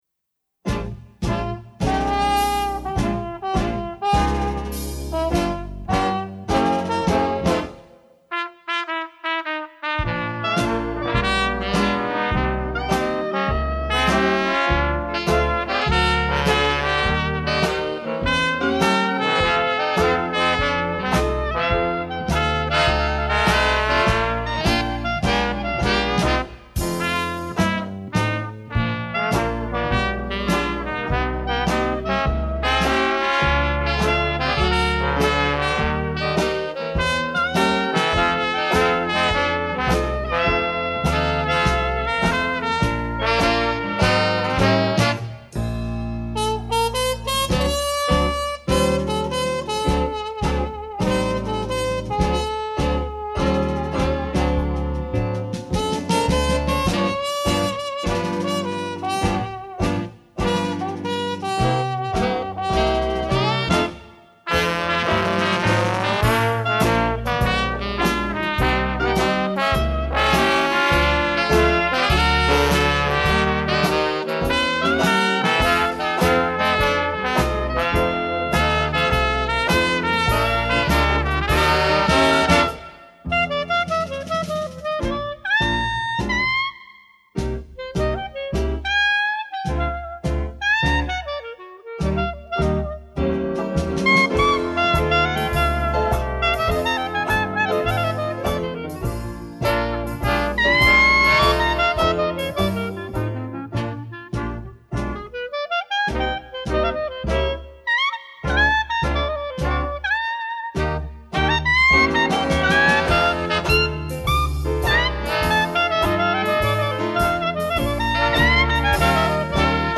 Gattung: Dixieland Combo